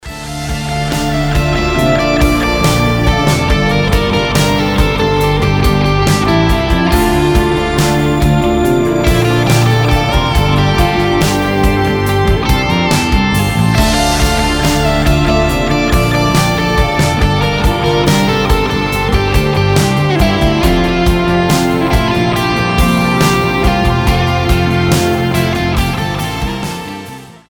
гитара
без слов
вдохновляющие
Прекрасная околороковая музыка на гитаре